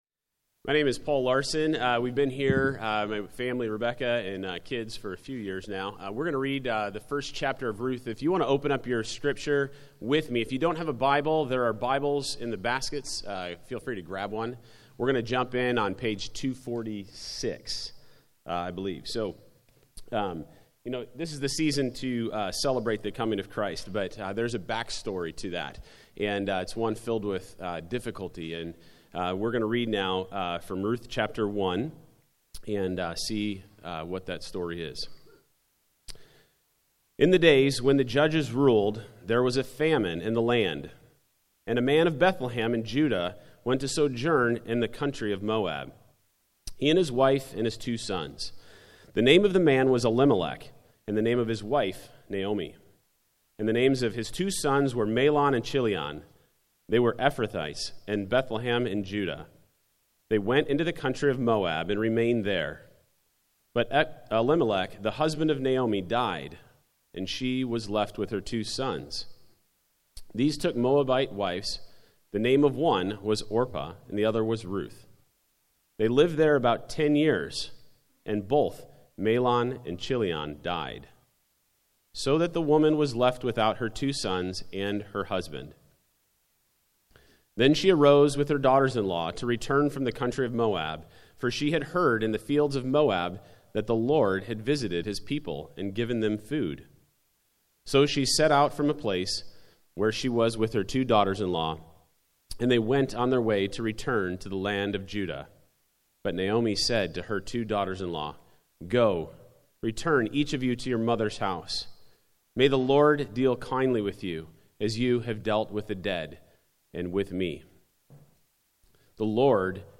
The December 2023 Sermon Audio archive of Genesis Church.